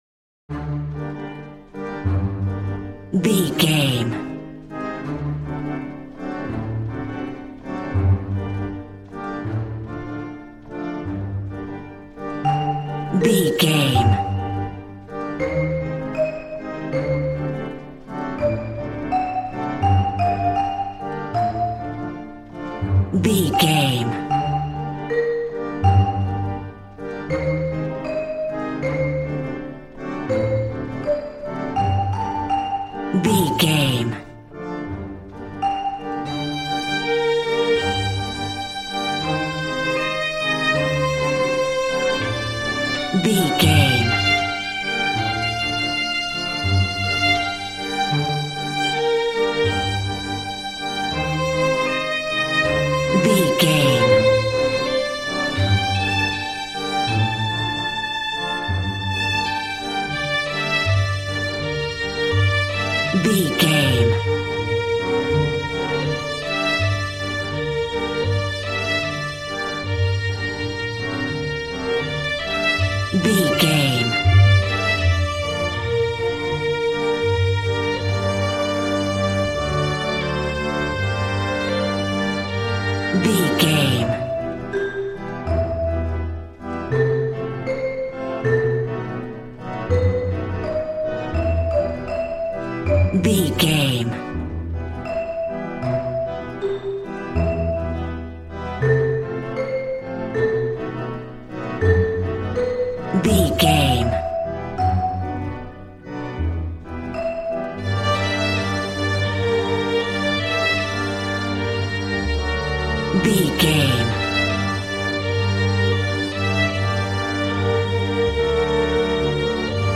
Aeolian/Minor
positive
cheerful/happy
joyful
drums
acoustic guitar